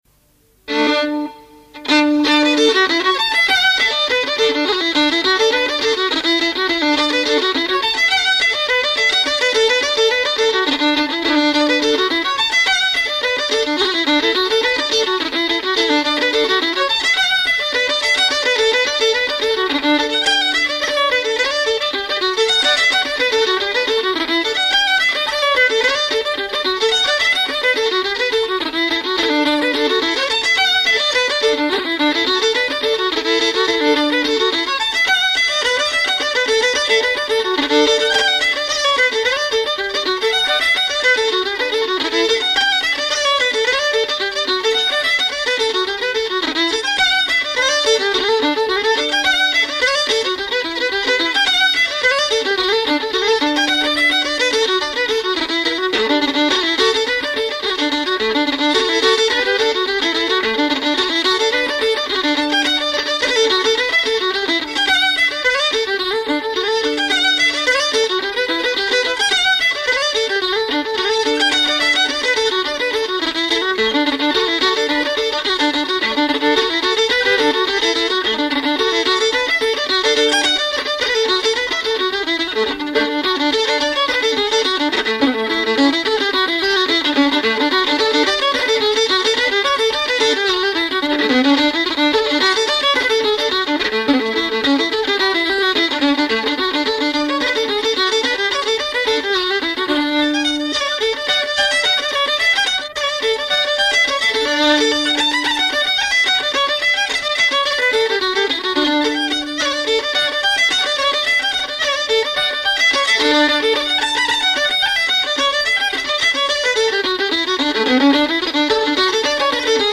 MP3s encoded from a 1988 cassette recording
D reels